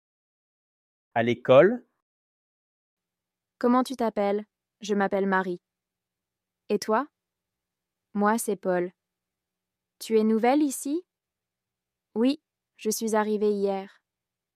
Ce dialogue simple met en scène deux élèves qui font connaissance à l’école.